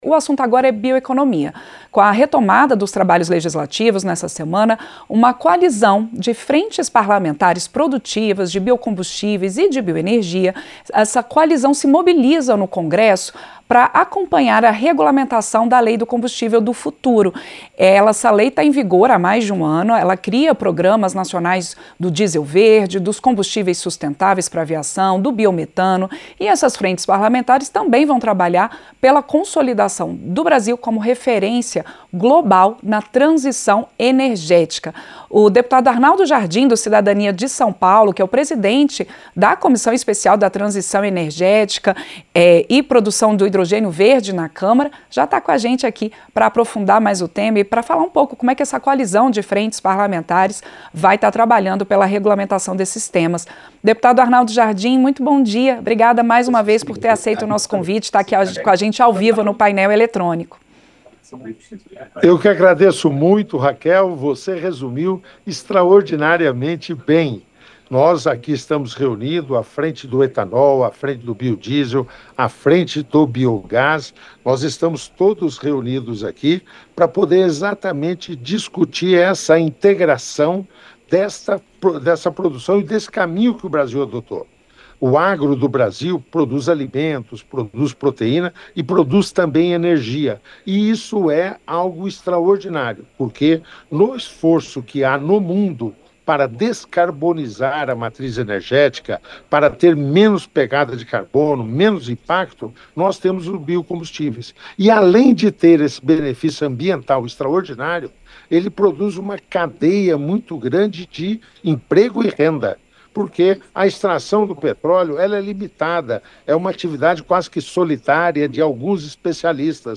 Entrevista - Dep. Arnaldo Jardim (Cidadania-SP)